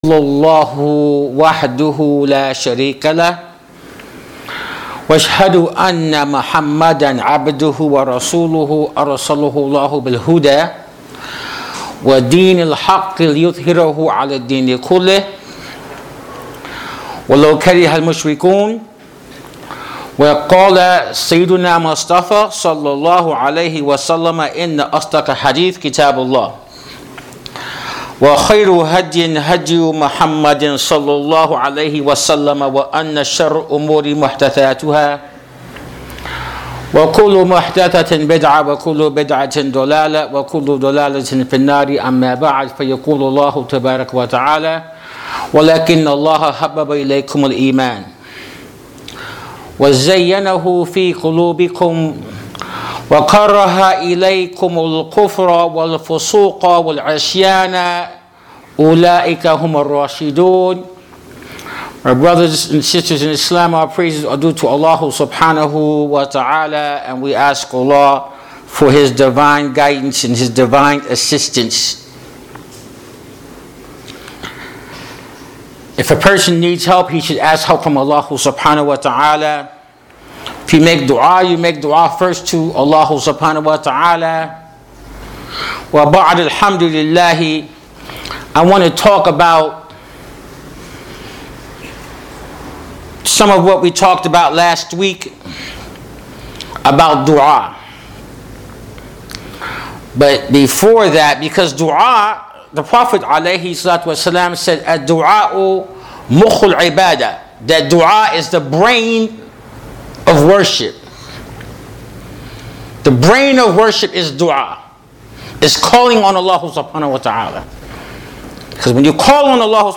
Audio Khutbatul Jum’ah; Love of Allah, The Greatest Love of All